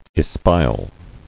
[es·pi·al]